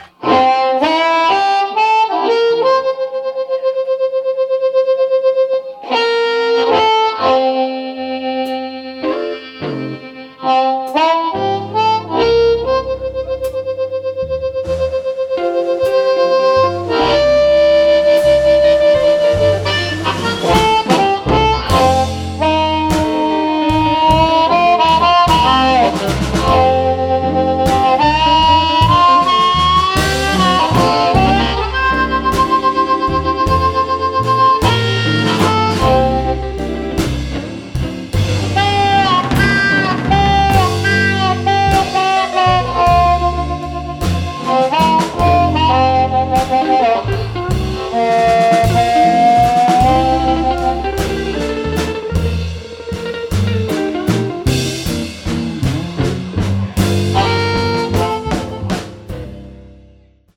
recorded live at Moe's Alley in Santa Cruz, California